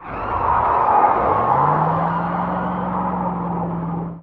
skidin3.ogg